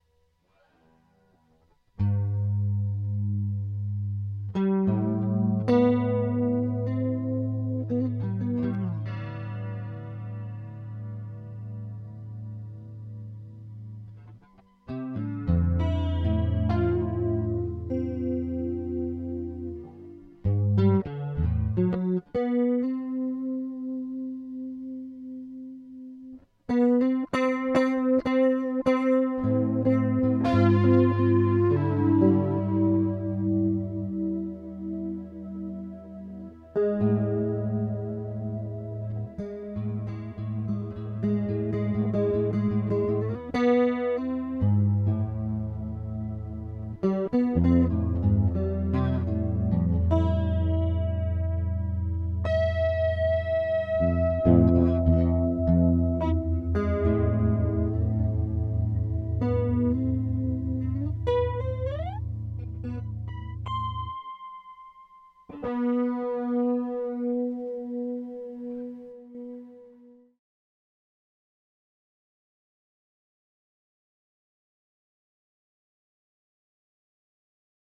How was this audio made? Improvised during a multimedia "doodle-jam" using a quickly generated set of pitches.